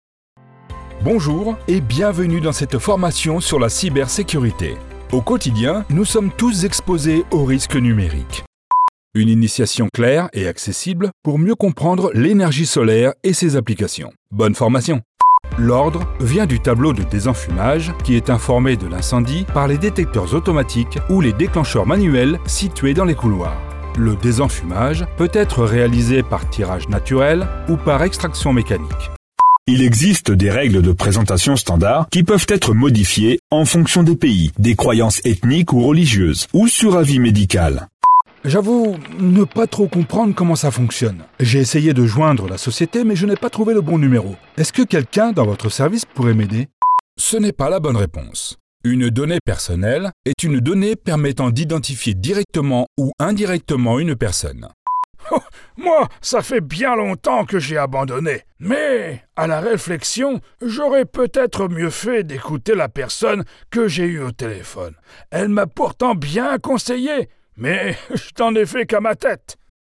Profonde, Naturelle, Chaude, Douce, Commerciale, Polyvalente
E-learning